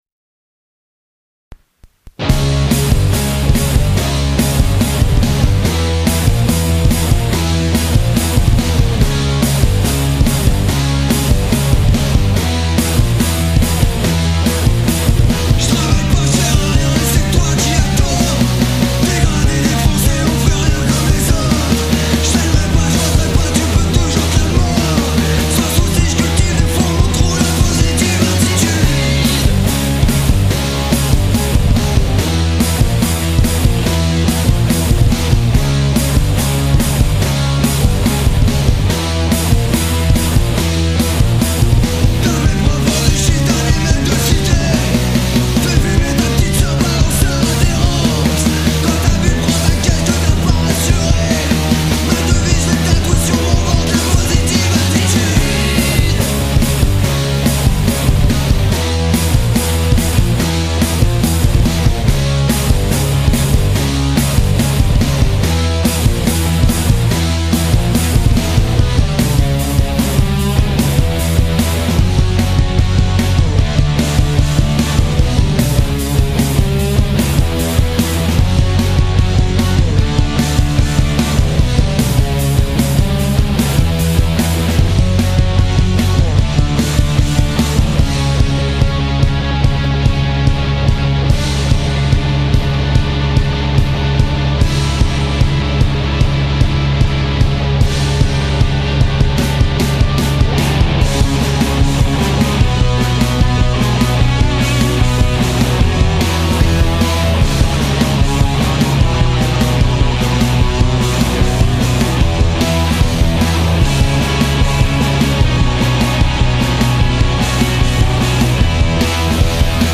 guitare voix boite à ryhtme